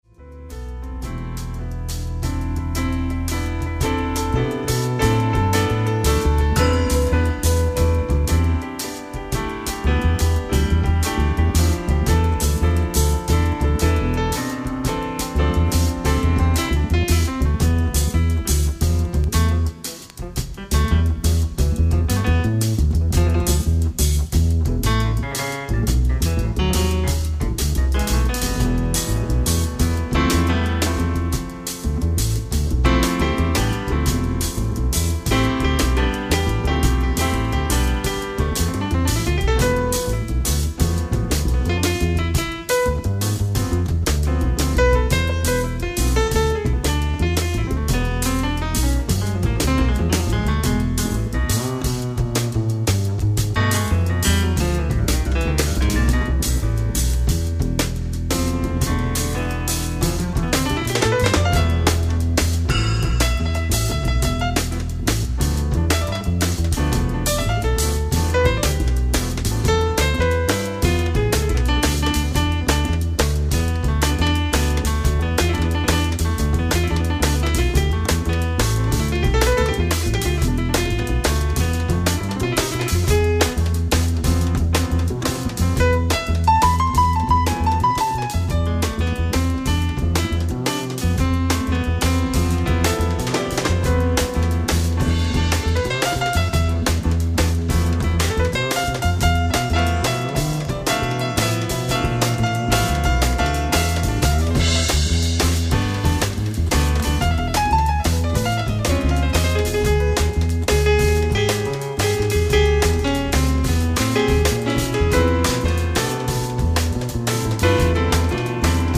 ライブ・アット・ロイヤル・フェスティバル・ホール、ロンドン、イングランド 11/14/2003
感激の超高音質盤！！
※試聴用に実際より音質を落としています。